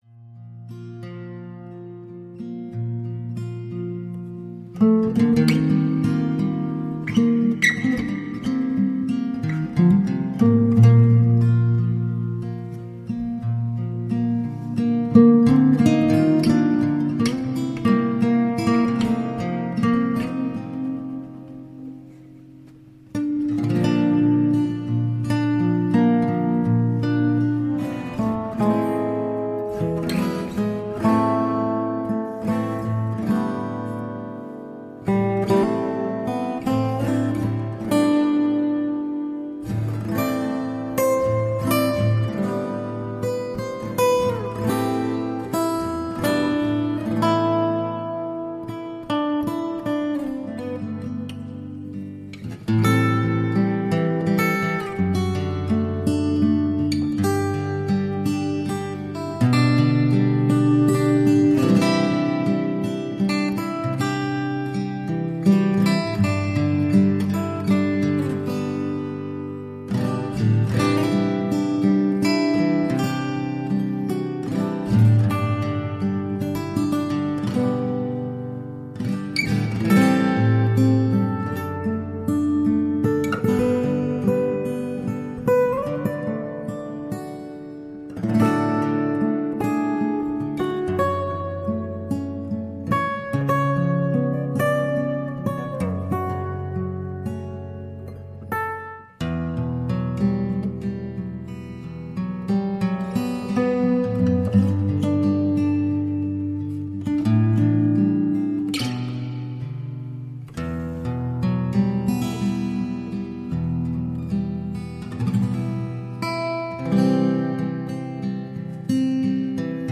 44.1 kHz / Stereo Sound